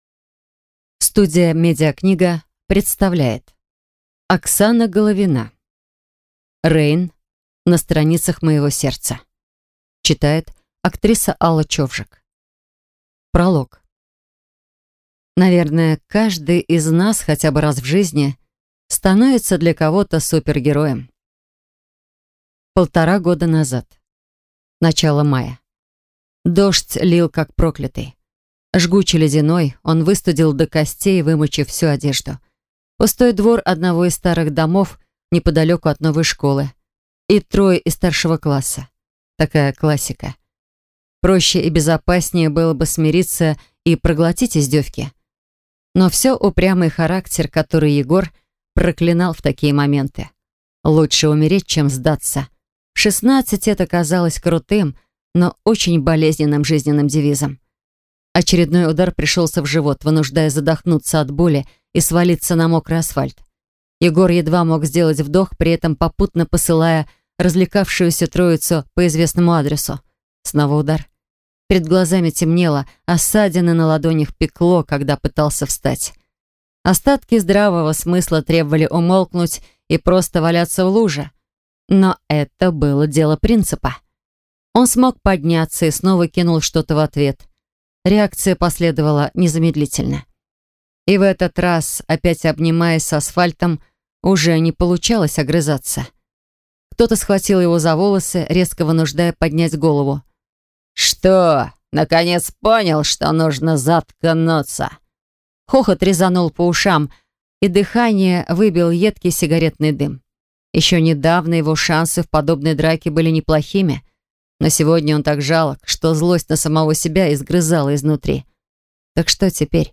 Аудиокнига Rain. На страницах моего сердца | Библиотека аудиокниг